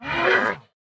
Minecraft Version Minecraft Version snapshot Latest Release | Latest Snapshot snapshot / assets / minecraft / sounds / mob / panda / cant_breed5.ogg Compare With Compare With Latest Release | Latest Snapshot
cant_breed5.ogg